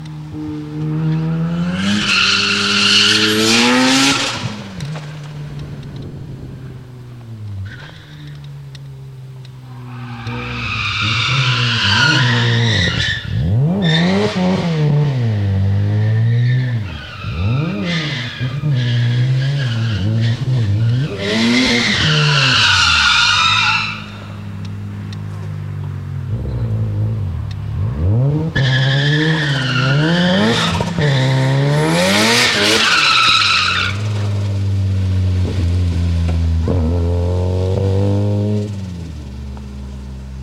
Rx7 Bat burnout video download
End of the day antics from Taupo 2003.